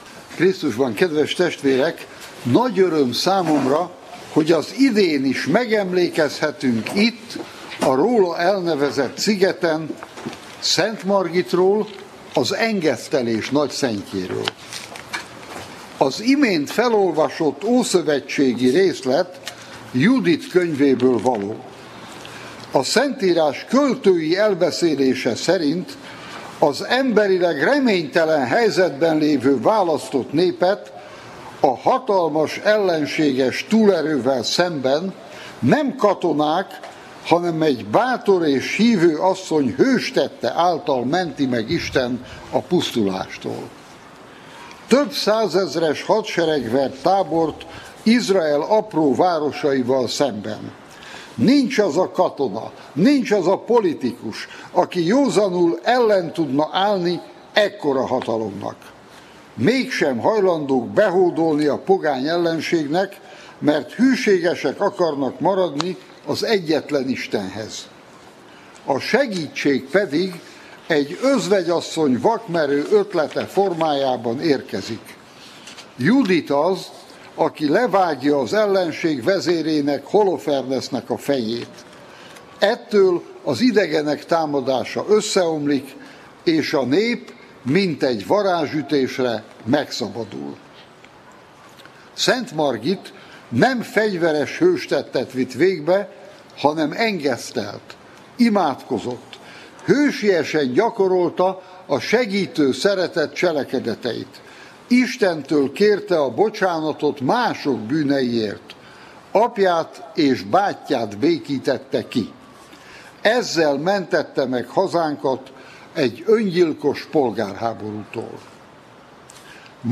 [Grem] [K:Spam] Erd� P�ter b�boros szentbesz�de (kb. 8 perc)
Azoknak, akik ma nem tudtak elj�nni a Margit-szigetre.